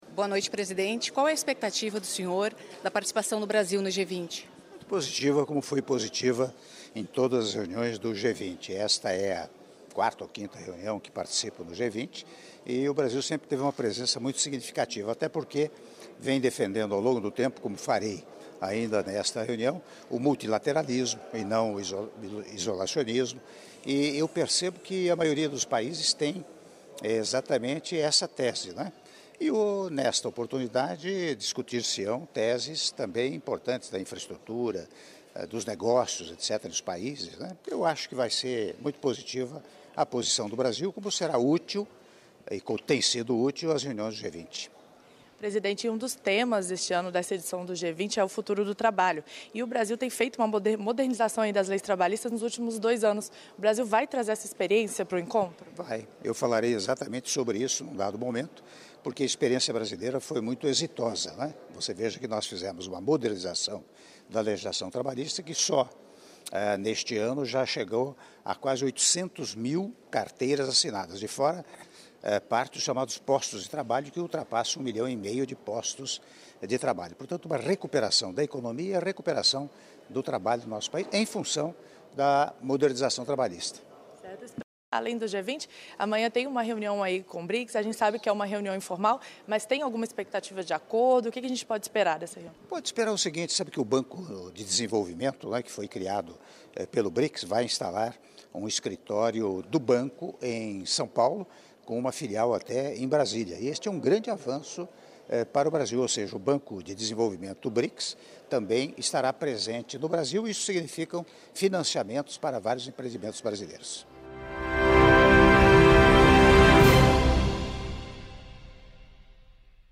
Áudio da Entrevista concedida pelo Presidente da República, Michel Temer, ao chegar em Buenos Aires para participar da XXIV Cúpula do G20 - Buenos Aires/AR (05min03s)